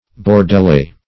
Search Result for " bordelais" : The Collaborative International Dictionary of English v.0.48: Bordelais \Bor`de*lais"\, a. [F.] Of or pertaining to Bordeaux, in France, or to the district around Bordeaux.
bordelais.mp3